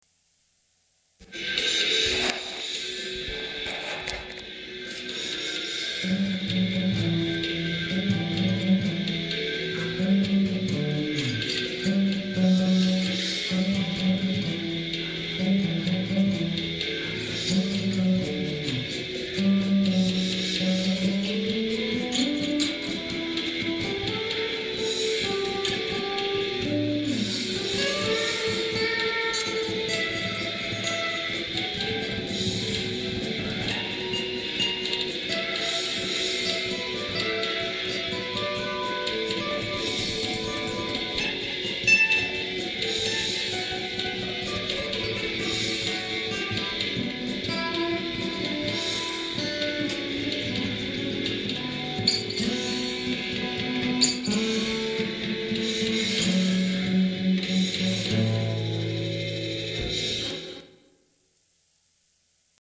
Качество нормальное, не как обычно :gg: